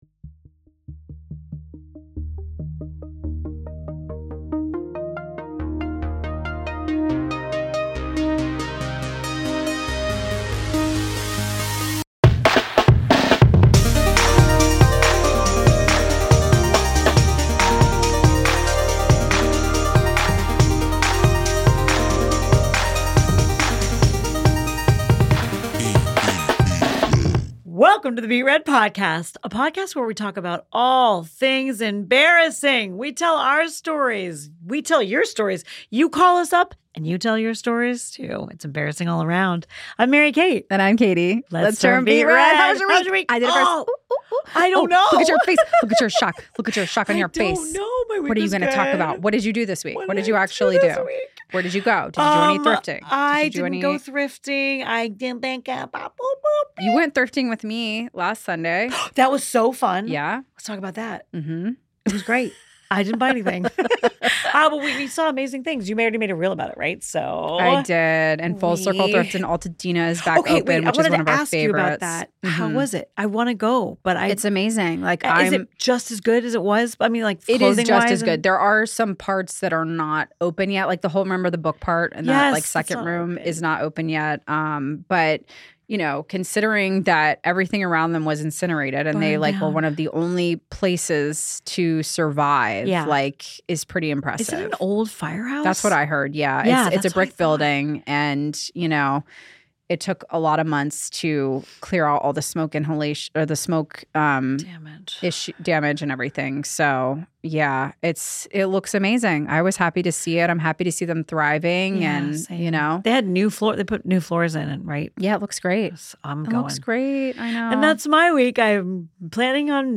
Uproarious laughter, glorious distraction and an addiction to hearing more relatable, humiliating tales from the hosts and their listeners.
… continue reading 46 Episoden # Comedy # Beet Red